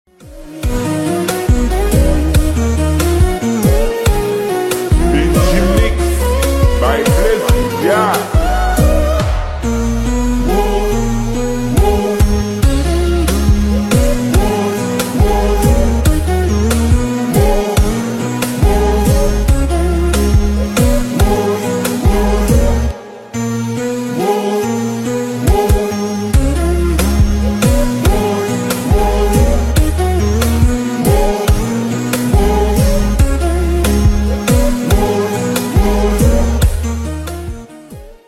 Kompa song slowed